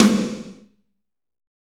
Index of /90_sSampleCDs/Northstar - Drumscapes Roland/DRM_Fast Rock/SNR_F_R Snares x